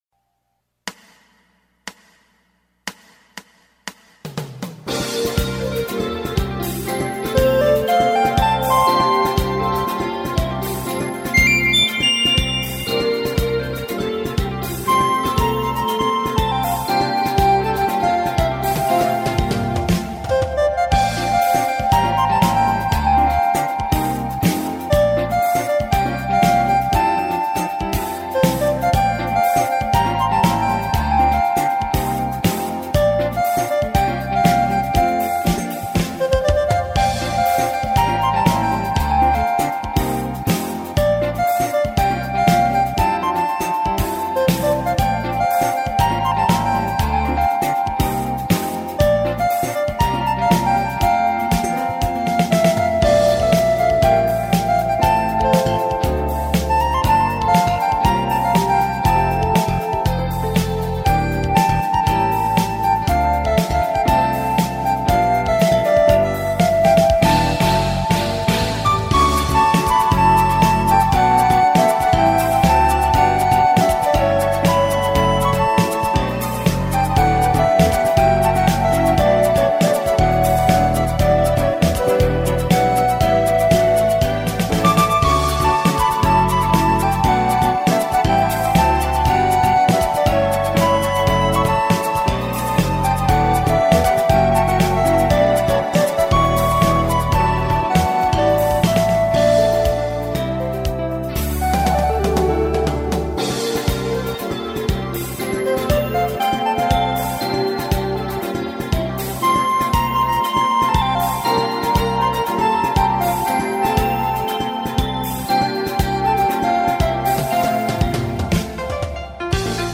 (중간에 툭툭 튀는 부분,,-_ㅡa)
소리도 되게 잘 잡으셧고요~